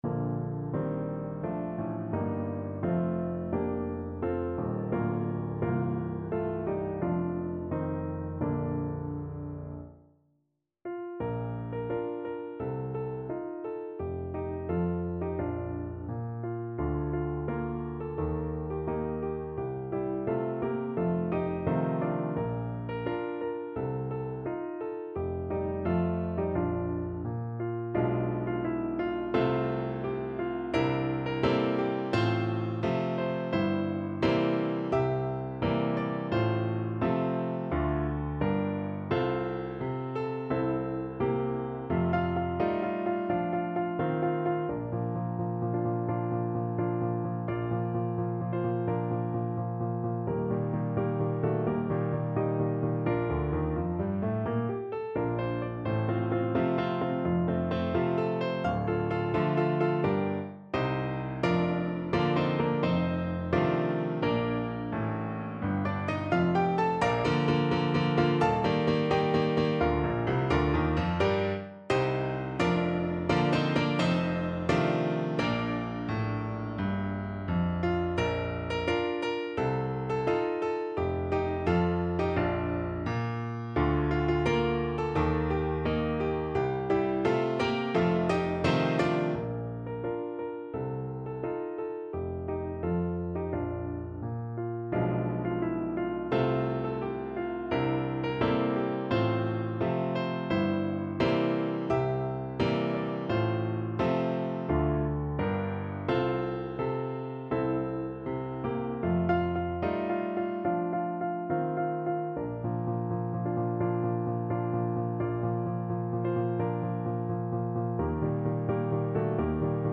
No parts available for this pieces as it is for solo piano.
4/4 (View more 4/4 Music)
Piano  (View more Advanced Piano Music)
Classical (View more Classical Piano Music)